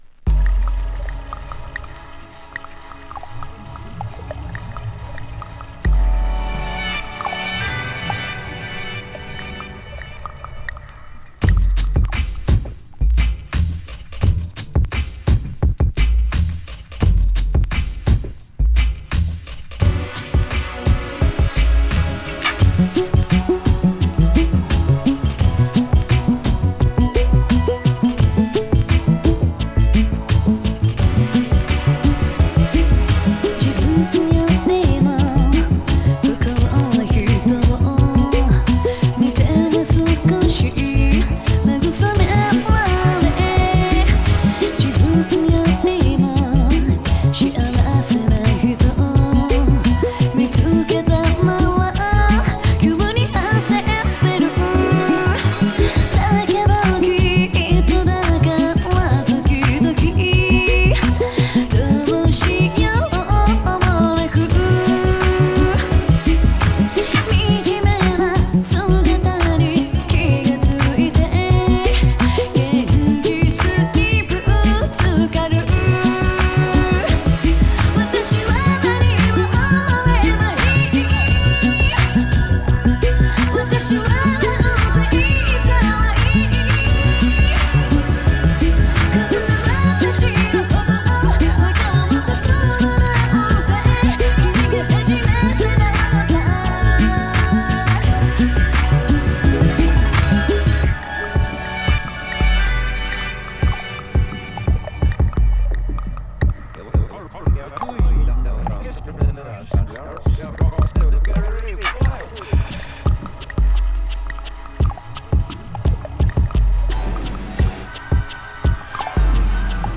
Dub